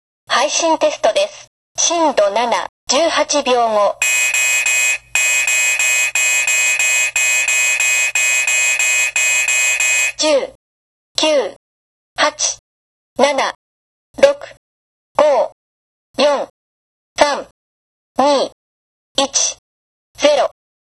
緊急地震速報アナウンス
緊急地震速報アナウンス音 震度７(WAV形式 約3.5MB